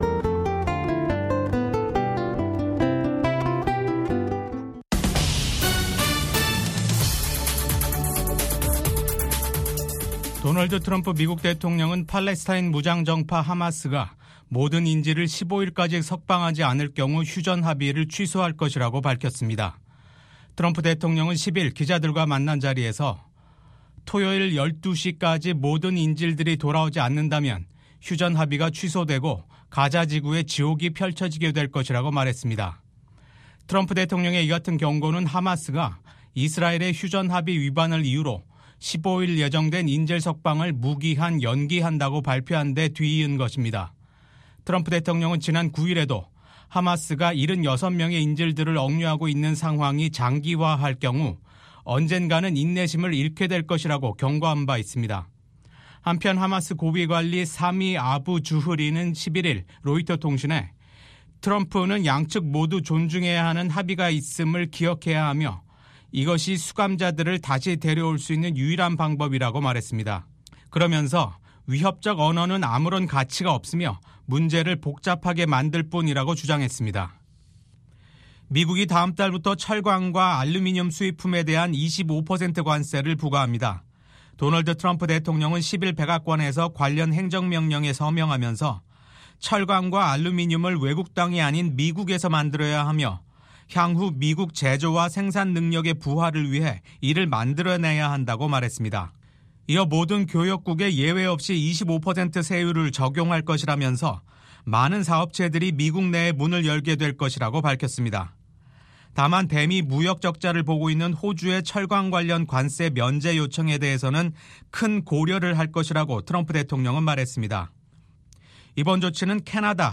VOA 한국어 아침 뉴스 프로그램 '워싱턴 뉴스 광장'입니다. 도널드 트럼프 미국 행정부 출범 이후 대미 담화 빈도를 크게 늘린 북한이 이번엔 미국의 원자력 추진 잠수함(SSN)의 부산 입항을 비난하는 담화를 냈습니다. 미국의 한반도 전문가들은 지난주 미일 정상회담이 북한 비핵화와 미한일 3국 공조를 강조한 것에 주목하며 한반도와 역내 평화와 안보에 기여할 것으로 기대했습니다.